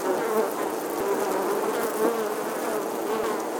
bee.mp3